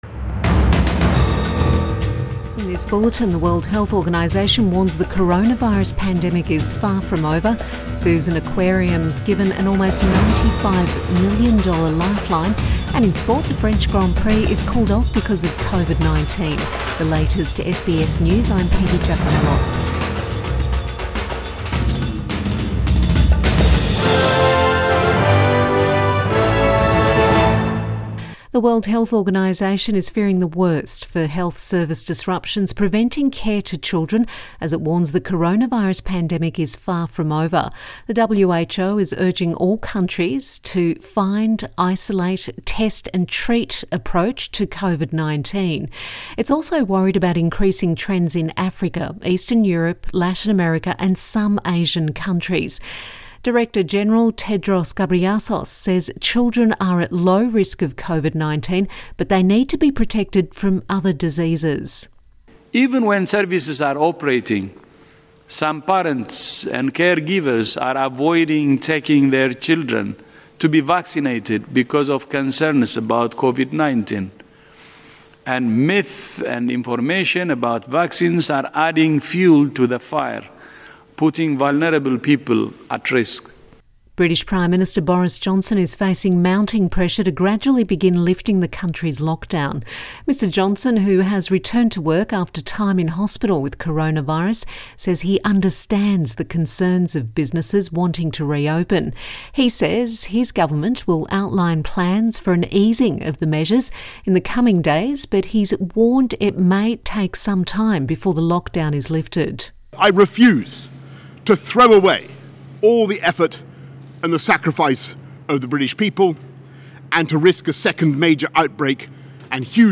AM bulletin 28 April 2020